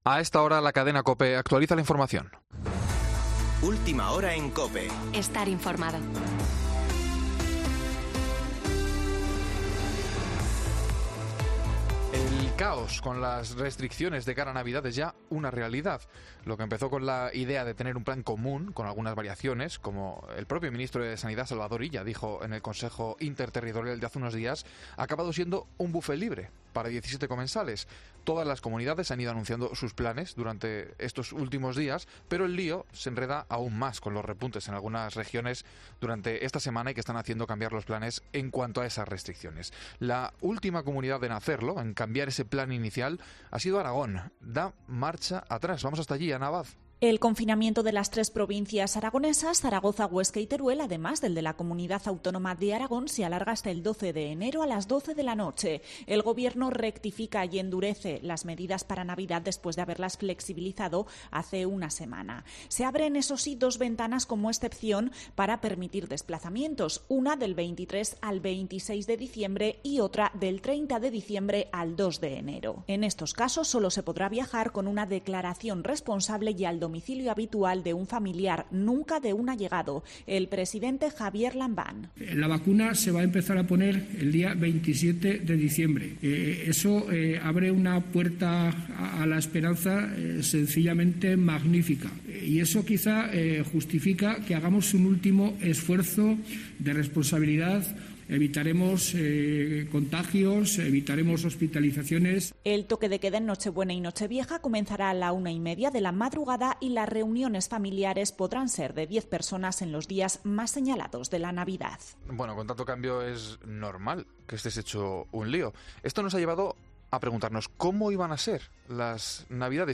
Boletín de noticias COPE del 19 de diciembre de 2020 a las 19.00 horas